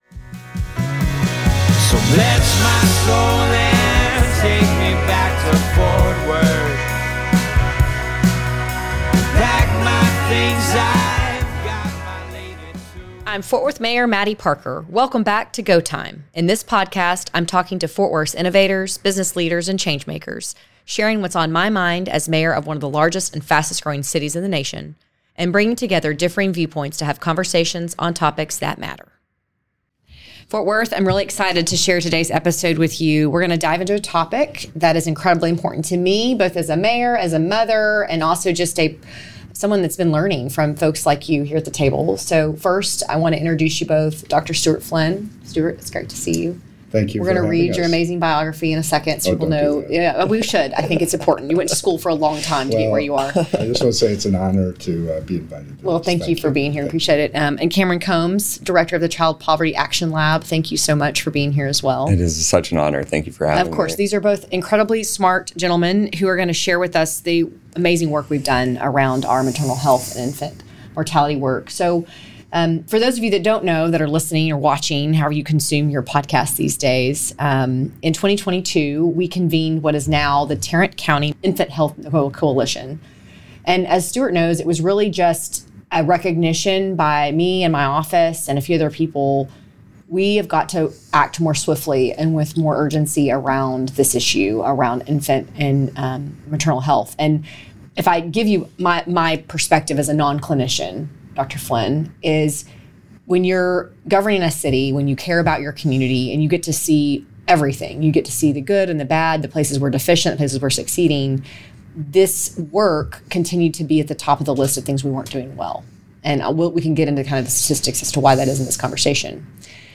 In the Go Time podcast, Mayor Mattie Parker sits down with Fort Worths innovators, business leaders, and changemakers; shares whats on her mind as Mayor of one of the largest and fastest growing cities in the nation; and brings together differing viewpoints to have conversations on topics that matter.